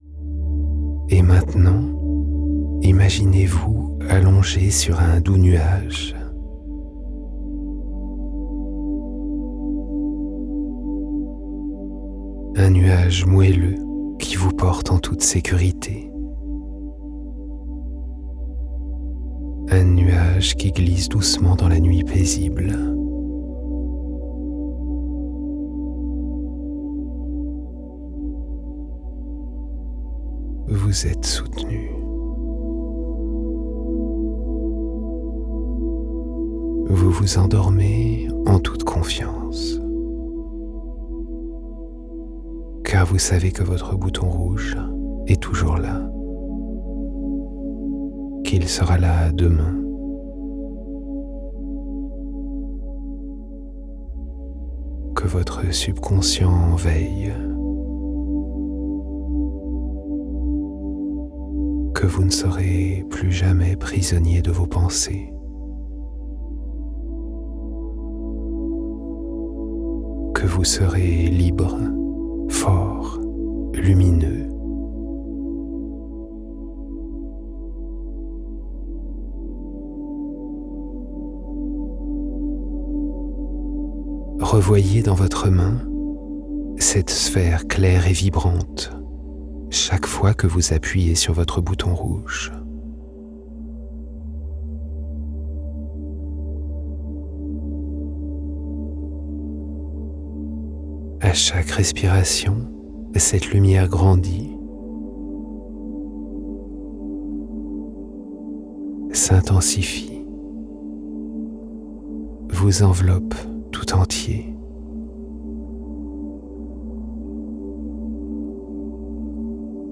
Pensée positive – Pensée négative: Hypnose guidée pour un sommeil profond et libérateur